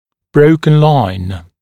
[‘brəukən laɪn][‘броукэн лайн]пунктирная линия